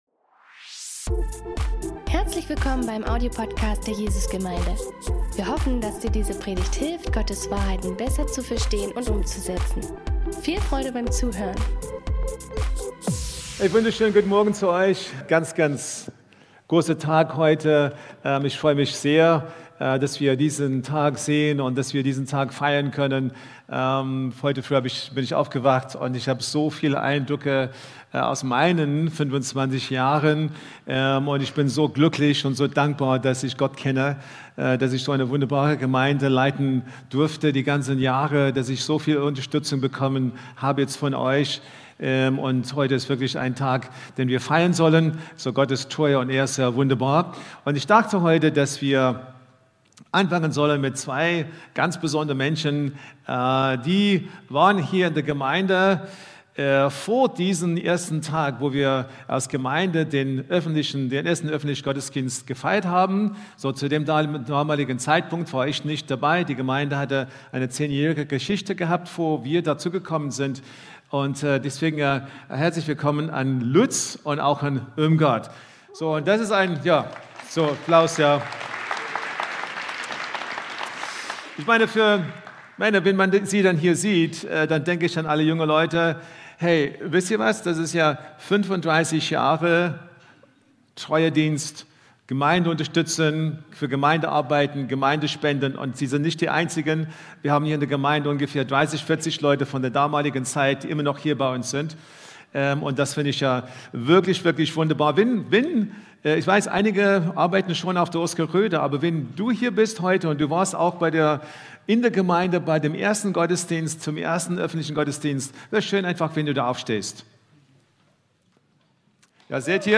Jesus Gemeinde Dresden | Predigten